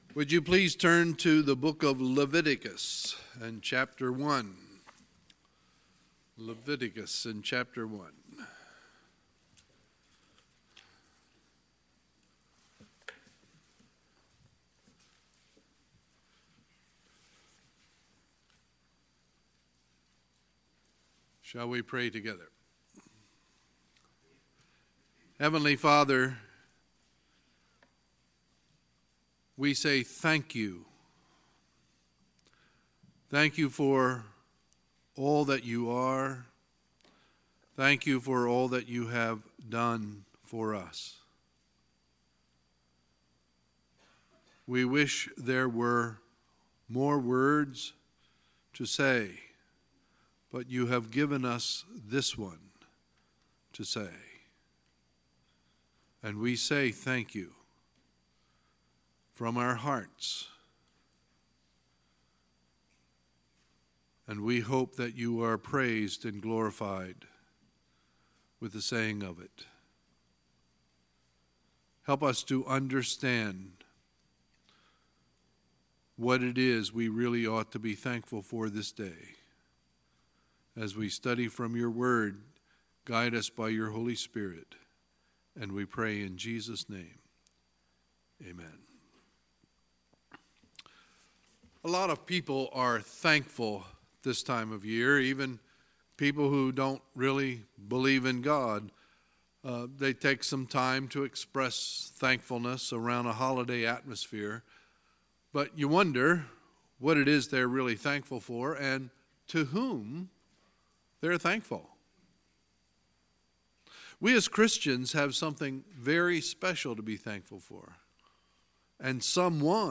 Sunday, November 18, 2018 – Sunday Morning Service